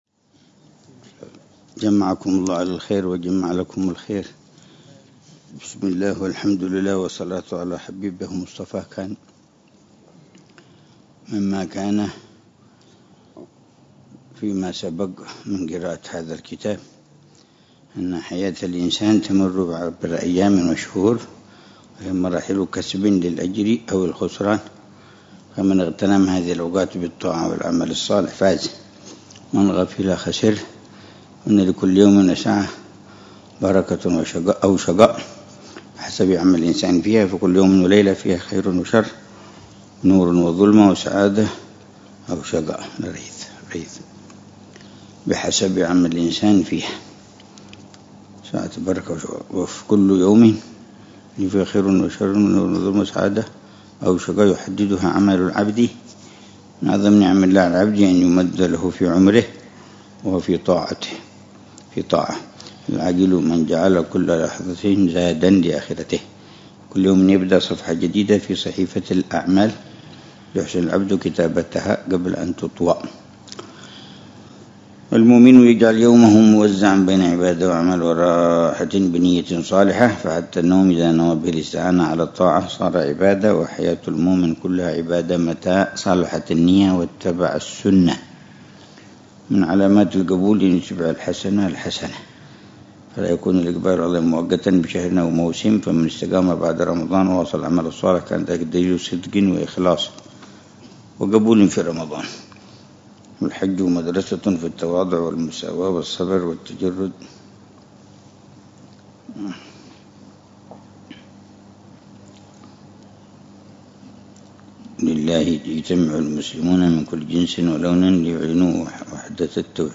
قراءة بتأمل وشرح واقعي لكتاب رسالة المعاونة للإمام عبد الله بن علوي الحداد، يلقيها الحبيب عمر بن محمد بن حفيظ لكبار طلاب حلقات المساجد